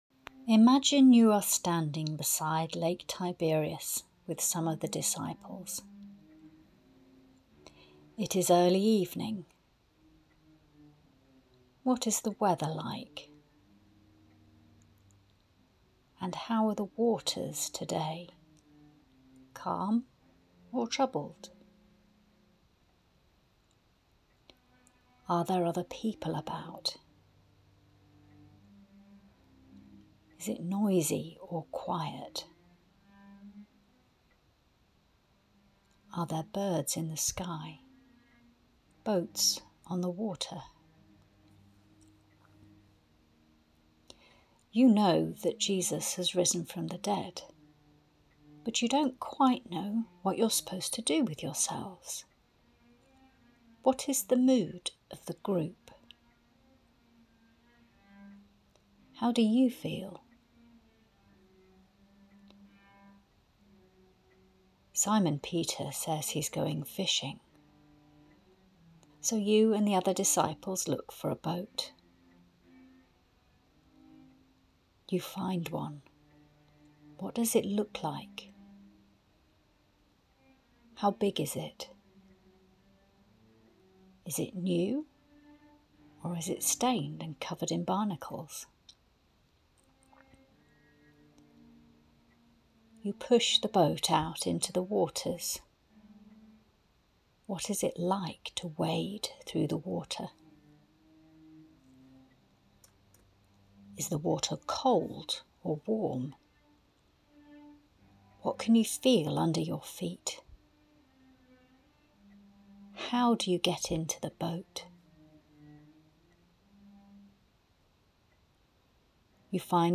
Guided meditation – a barbecue on the beach